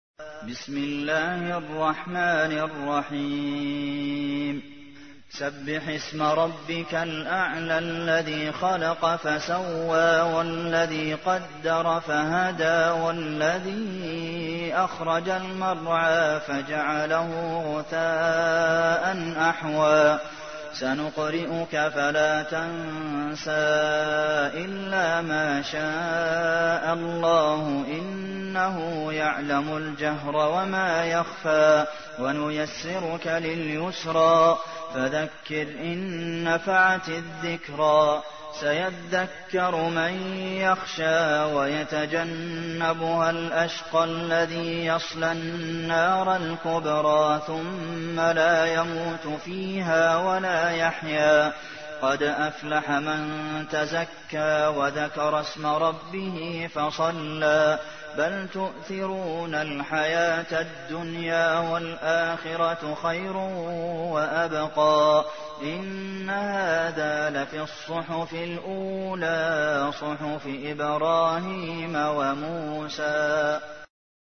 تحميل : 87. سورة الأعلى / القارئ عبد المحسن قاسم / القرآن الكريم / موقع يا حسين